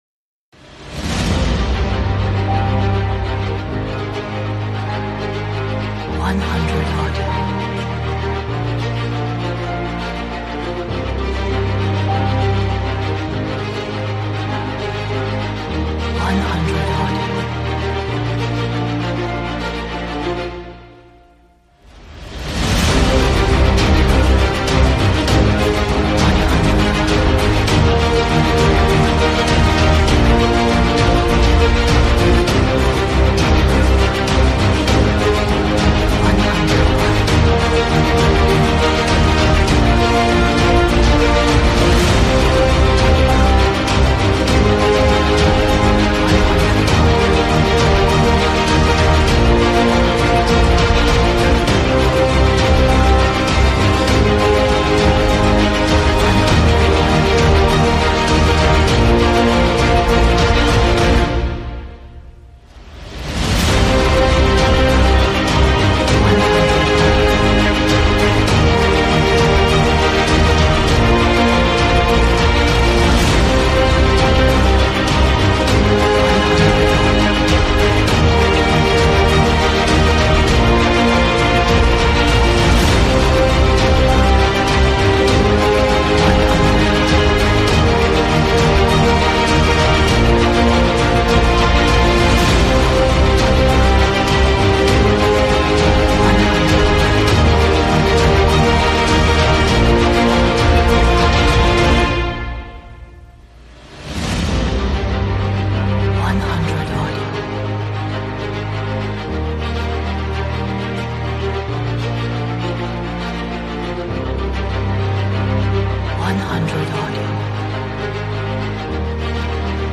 Atmospheric, dramatic, epic, energetic,